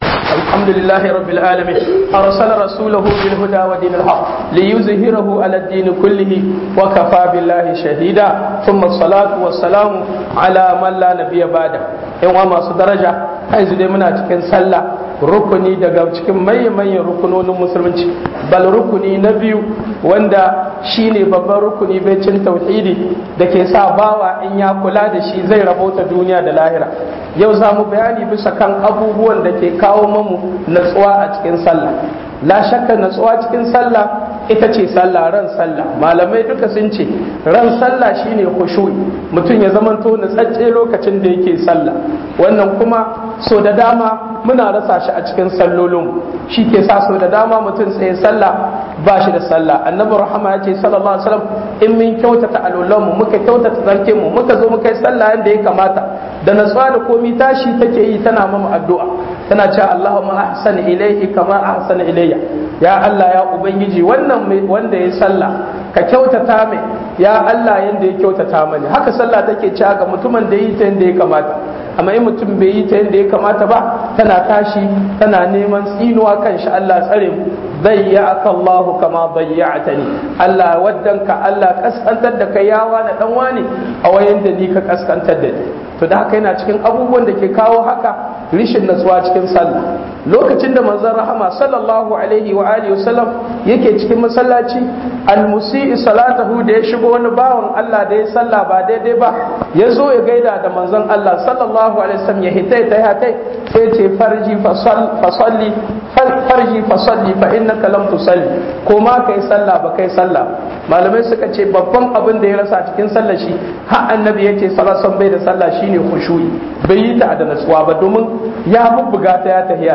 Hudubar Juma'a masalacin Morgane- 22 Fév 2025